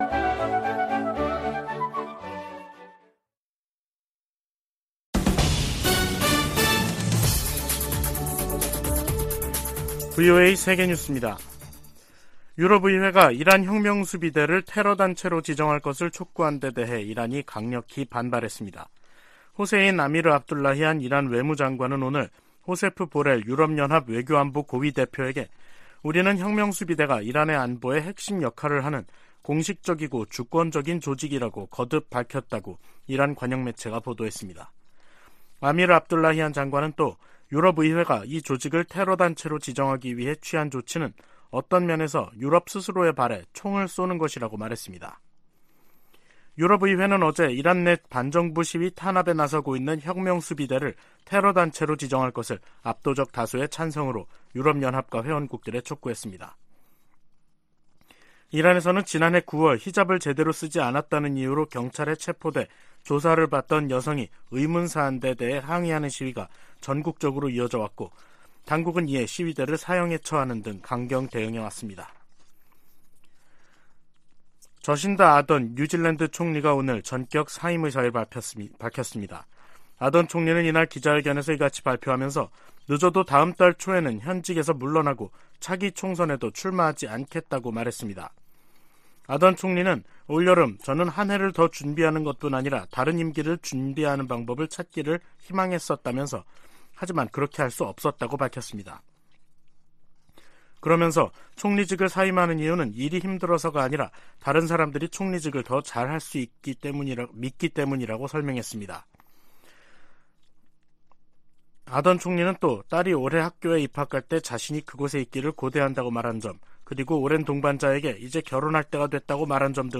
VOA 한국어 간판 뉴스 프로그램 '뉴스 투데이', 2023년 1월 19일 2부 방송입니다. 북한이 핵보유국을 자처해도 미국의 한반도 비핵화 목표에는 변함이 없다고 국무부가 밝혔습니다. 김정은 국무위원장이 불참한 가운데 열린 북한 최고인민회의는 경제난 타개를 위한 대책은 보이지 않고 사상 통제를 강화하는 조치들을 두드러졌다는 분석이 나오고 있습니다.